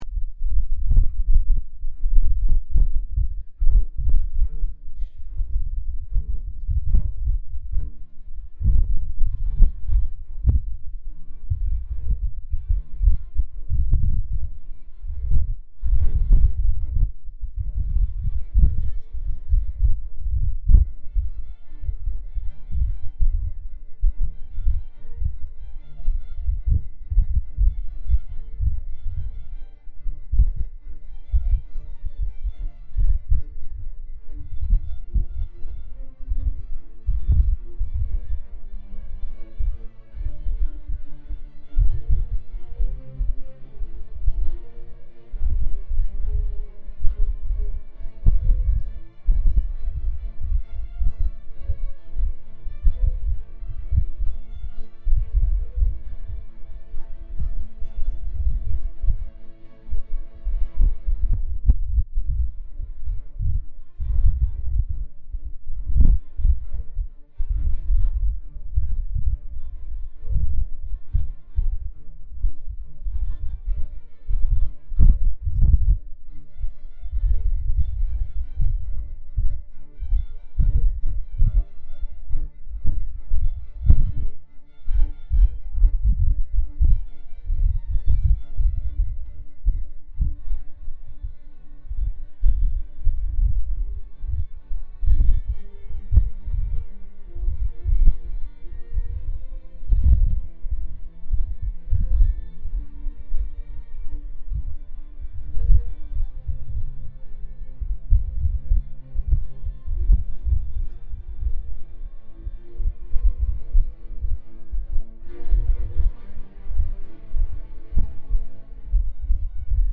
Palladio - Senior Strings